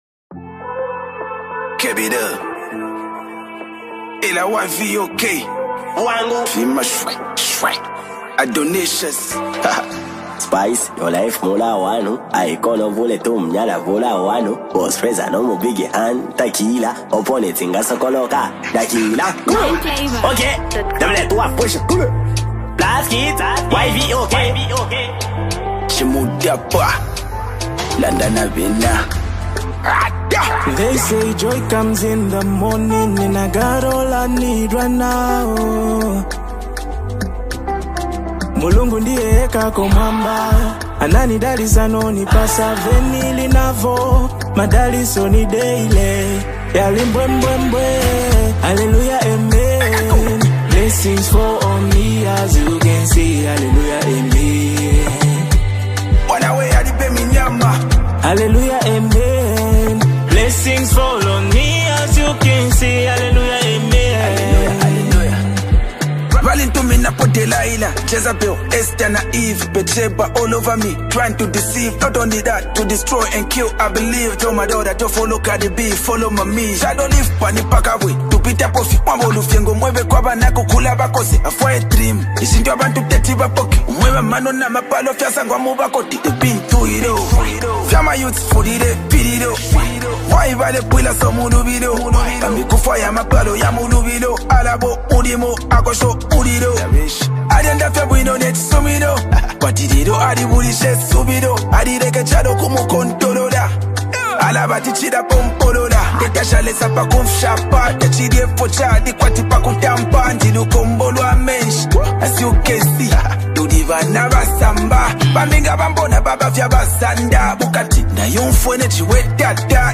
Zambian rap
impressive vocal blend, and clean production